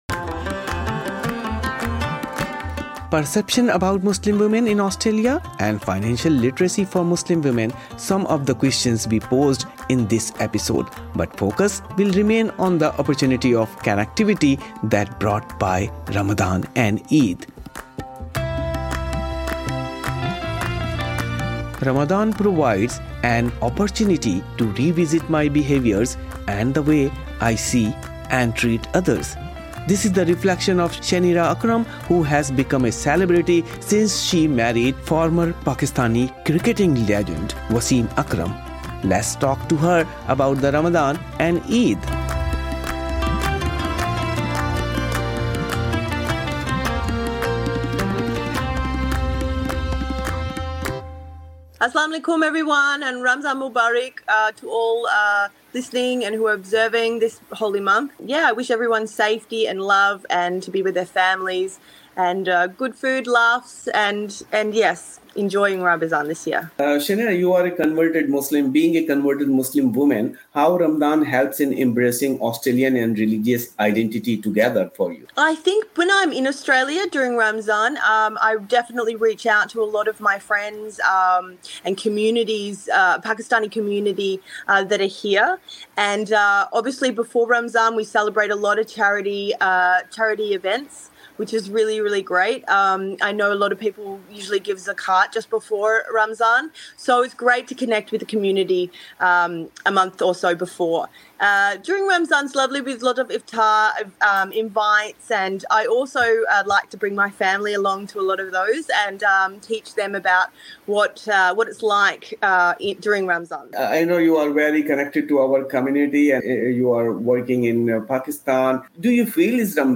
یہ انٹرویو انگریزی میں ہے۔